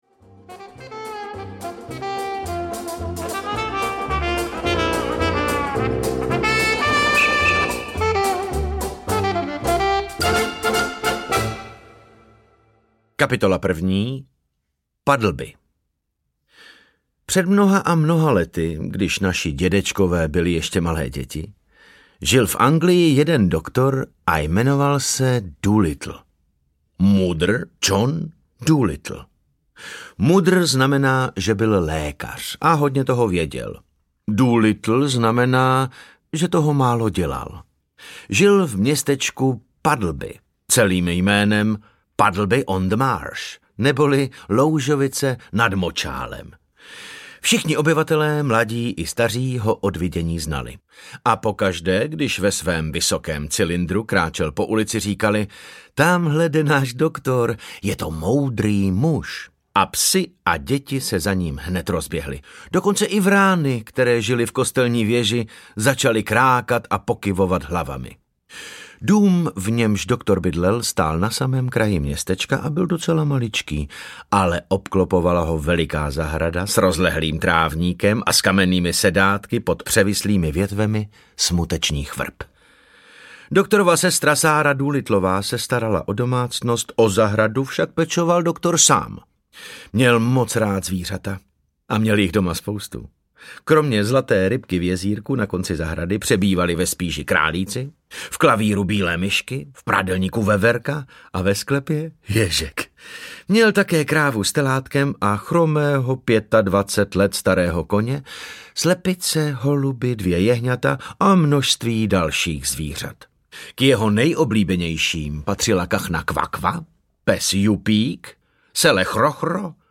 Doktor Dolittle a jeho zvířátka audiokniha
Ukázka z knihy
Oblíbený herec David Novotný svým hlasem mistrně oživil nejen postavu dobrosrdečného veterináře, ale i řadu jeho zvířecích kamarádů.
• InterpretDavid Novotný